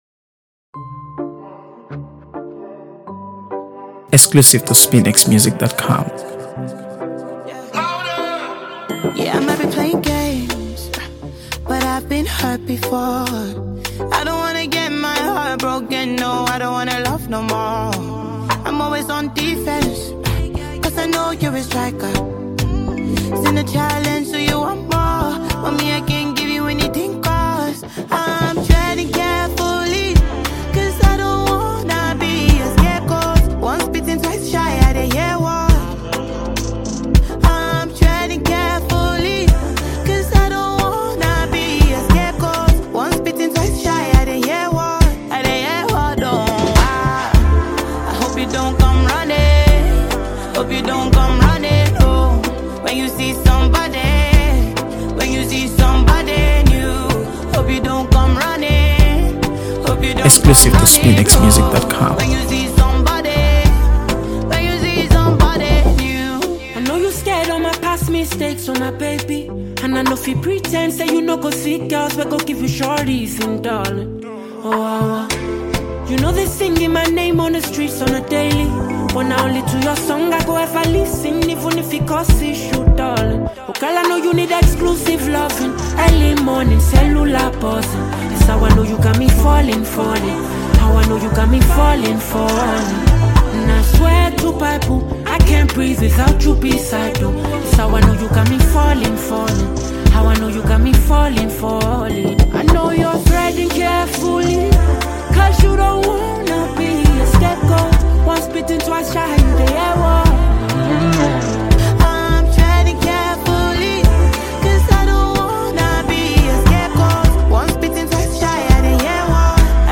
AfroBeats | AfroBeats songs
infectious rhythm